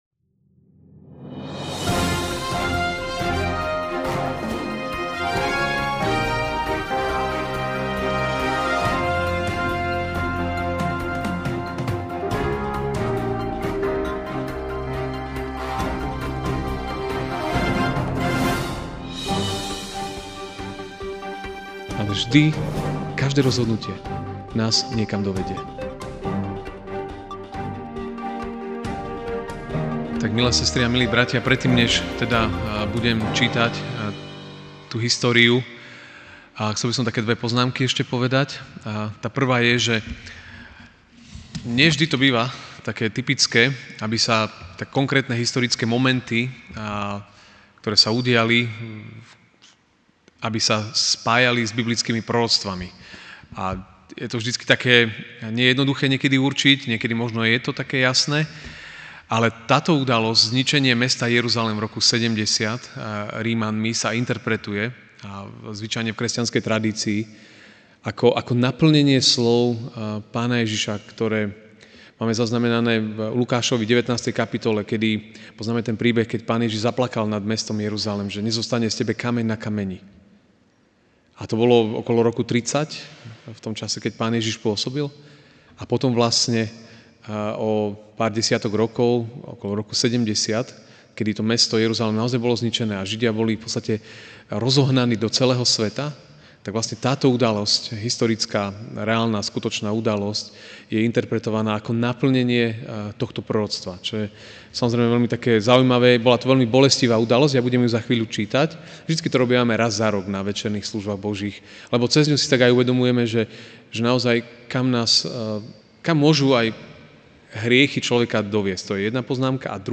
Večerná kázeň: Čítaná história zničenia Jeruzalema